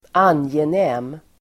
Uttal: [²'an:jenä:m]